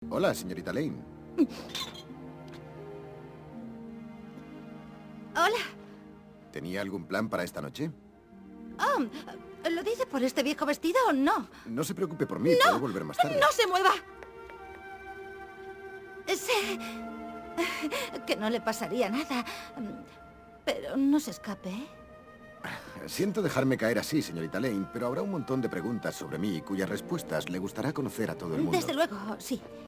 redoblaje (disco 2).
En el segundo disco se encuentra el nuevo doblaje (del año 2001), en un glorioso Dolby Digital 5.1, en el que la música y efectos suenan prácticamente igual que en la pista de audio en inglés. Además, las voces dobladas nuevas no desentonan demasiado del conjunto al haber sido grabadas de nuevo, si bien en ocasiones los diálogos se confunden demasiado con el resto de elementos de la pista sonora.
redoblaje.mp3